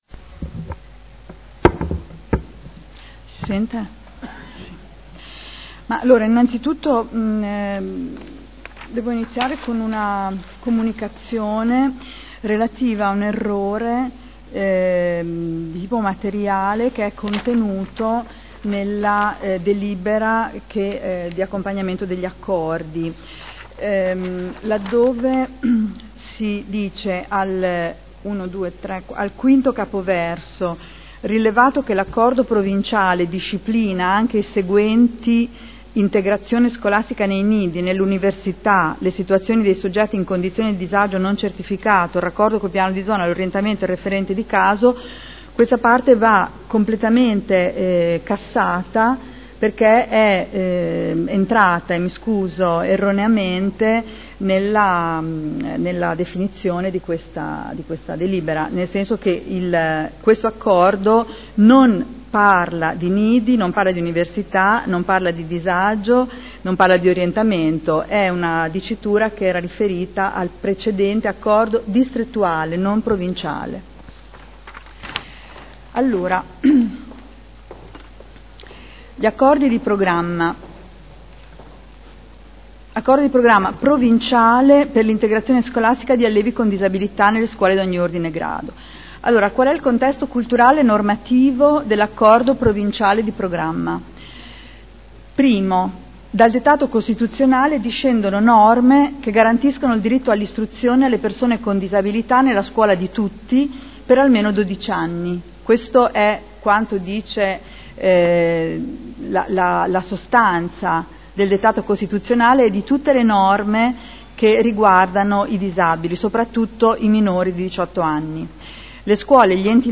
Seduta del 07/05/2012.